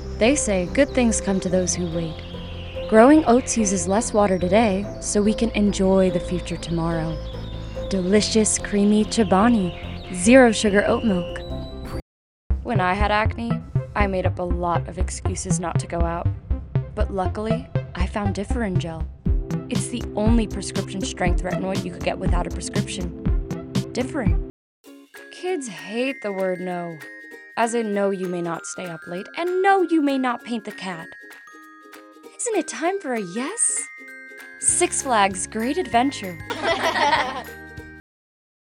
Commercial VO Demo Reel.wav